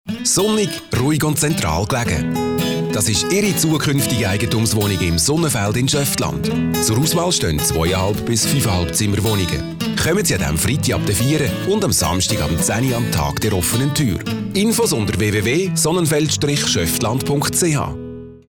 Werbung Schweizerdeutsch (AG)